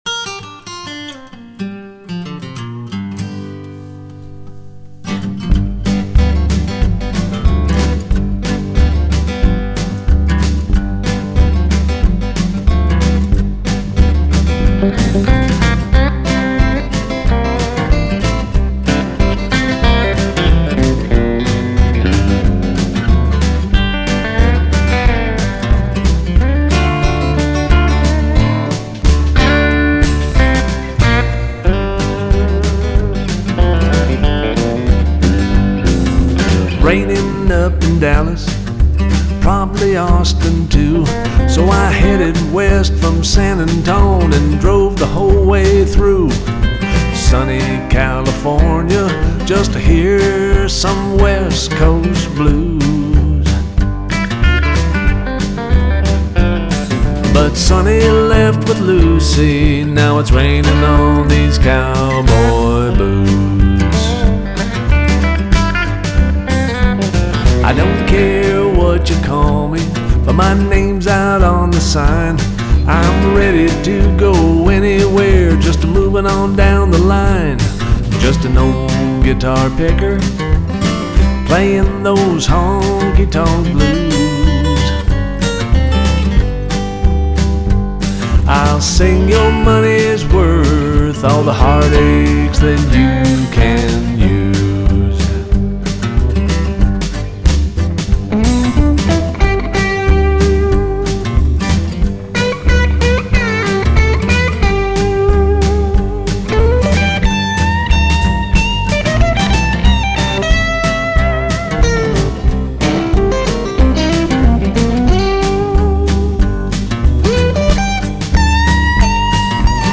vocals, acoustic guitar
67 Telecaster